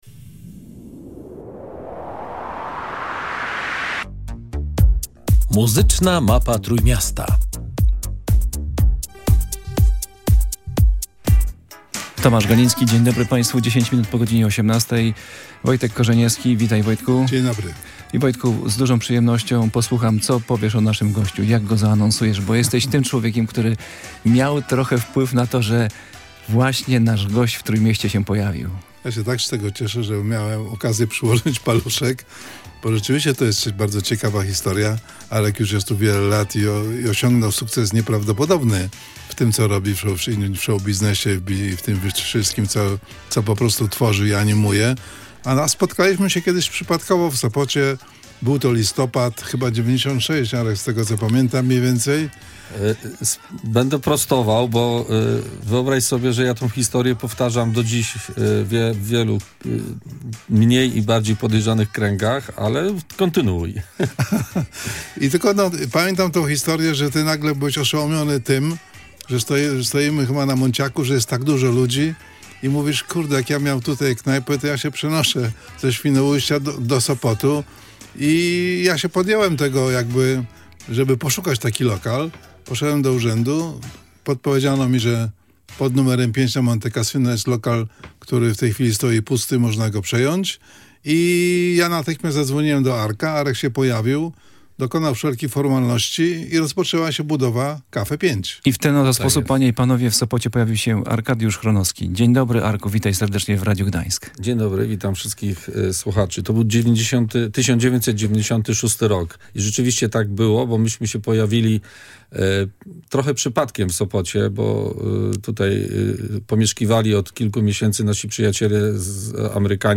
wyjątkową rozmowę